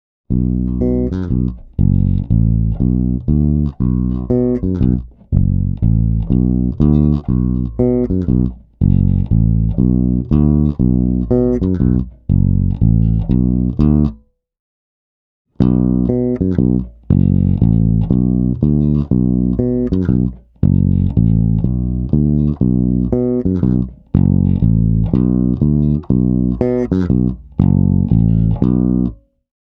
1. Všem nám známý rif od PF je ideální na prsty. Dal jsem za sebe nejdřív suchý, potom s kompresorem.